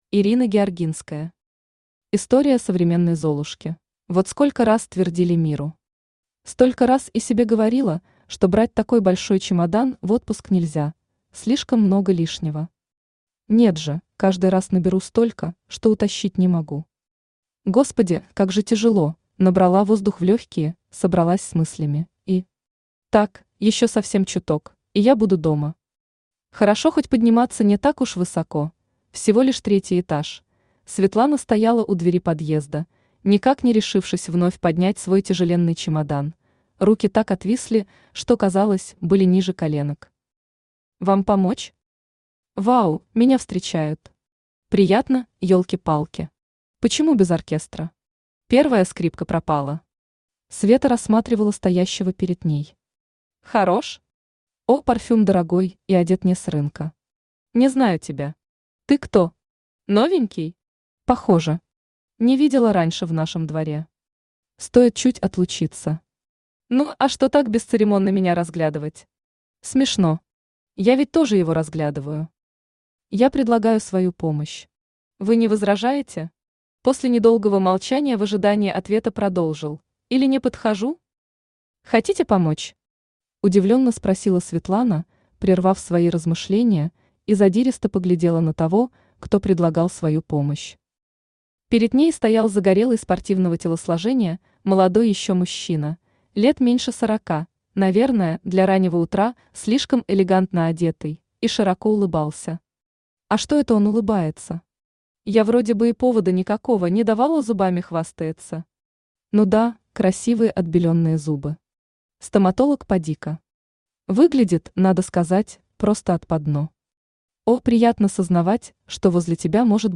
Аудиокнига История современной Золушки | Библиотека аудиокниг
Aудиокнига История современной Золушки Автор Ирина Георгинская Читает аудиокнигу Авточтец ЛитРес.